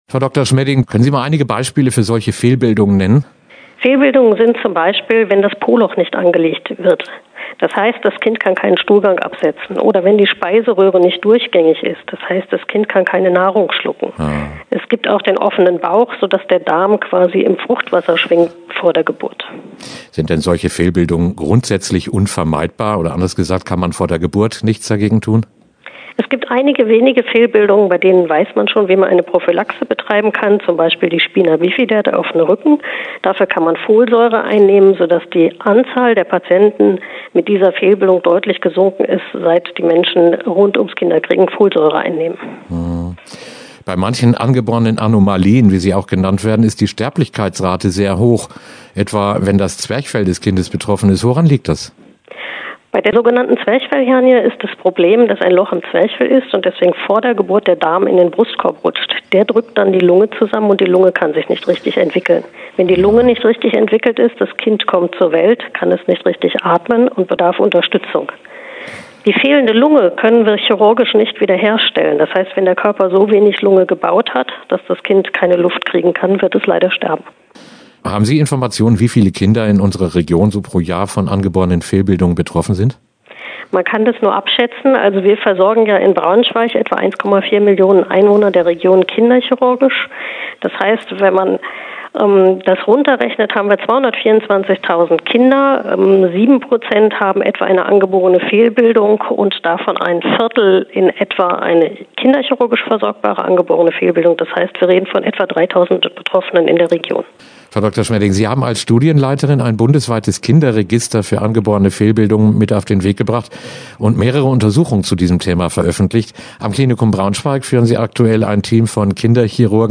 Interview Angeborene Fehlbildungen
Interview-Angeborene-Fehlbildungen.mp3